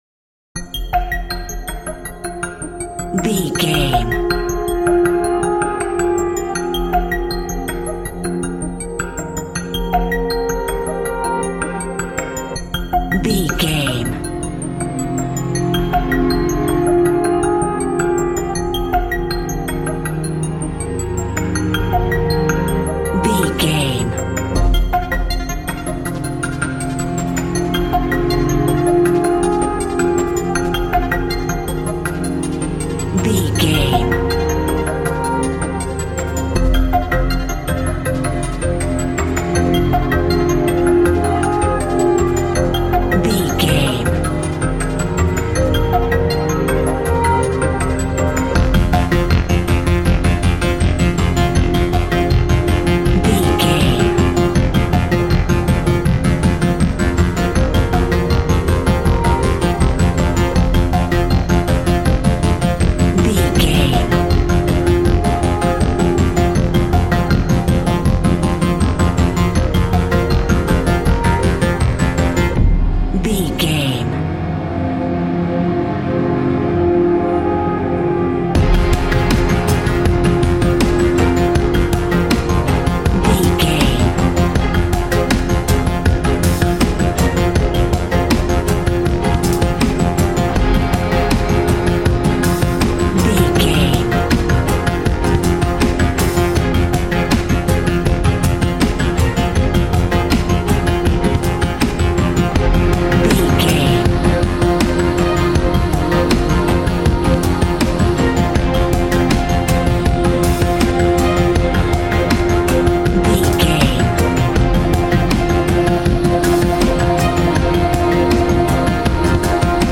Aeolian/Minor
synthesiser
ominous
dark
suspense
haunting
tense
creepy
spooky